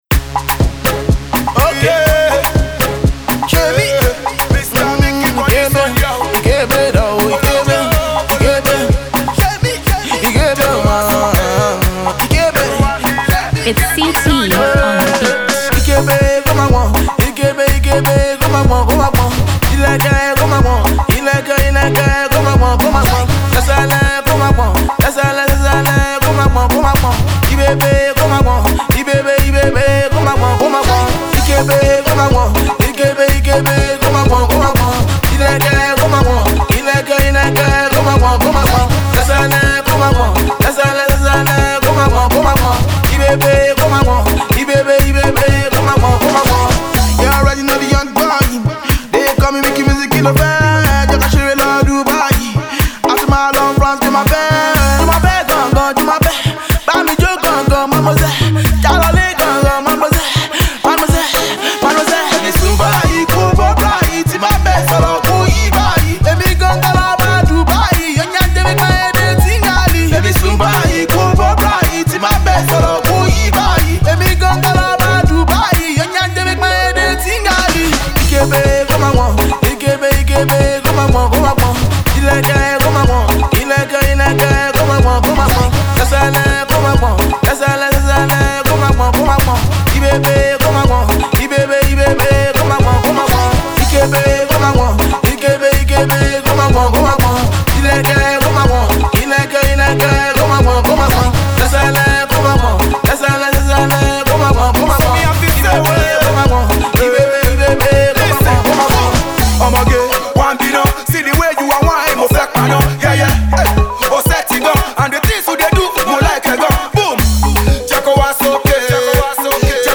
massive street anthem